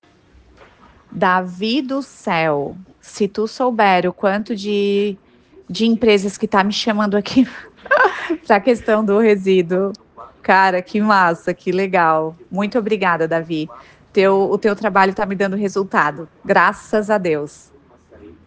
Depoimentos